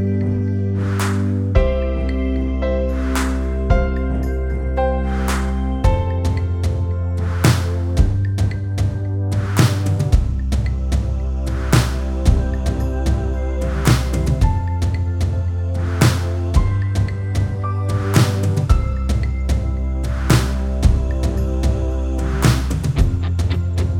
no Backing Vocals Christmas 3:42 Buy £1.50